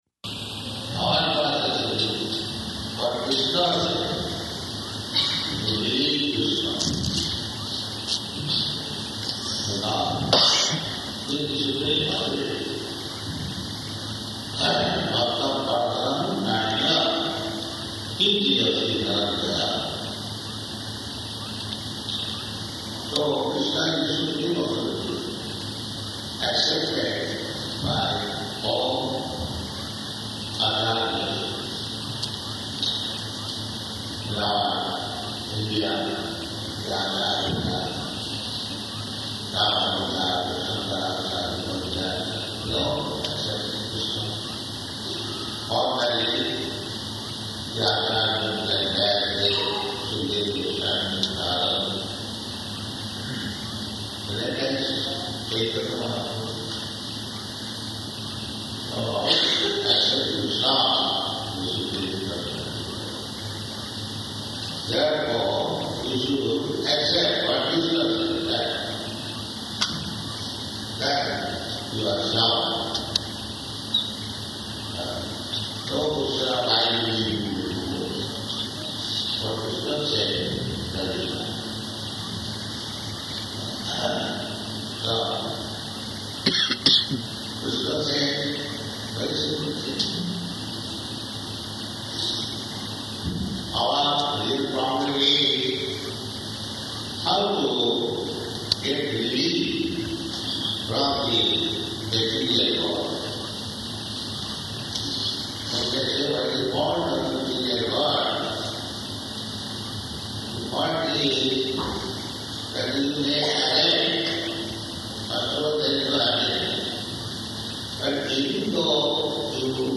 Arrival --:-- --:-- Type: Lectures and Addresses Dated: June 11th 1976 Location: Detroit Audio file: 760611AR.DET.mp3 Prabhupāda: ...our process is = What Kṛṣṇa says, we believe Kṛṣṇa, because He is the supreme authority.